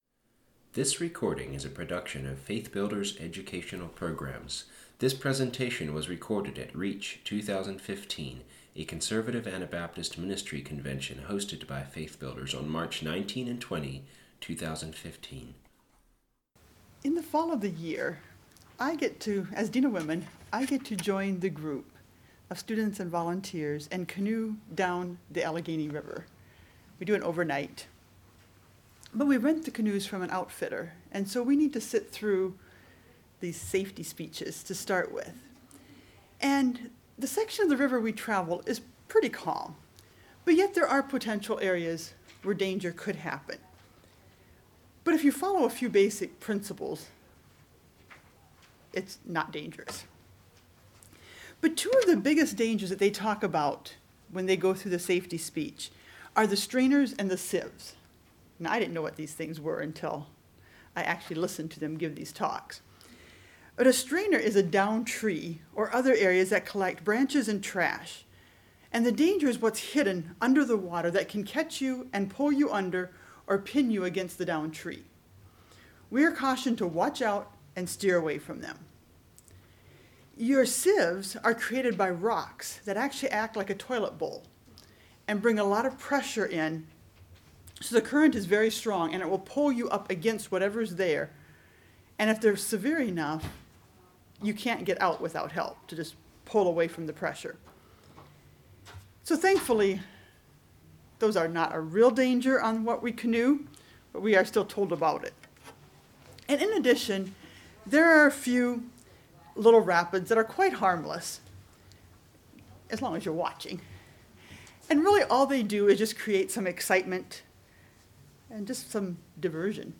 Home » Lectures » Joy in the Office